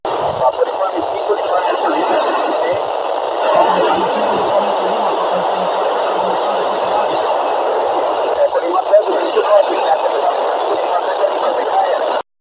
made a successful 2-way FM contact via SO-121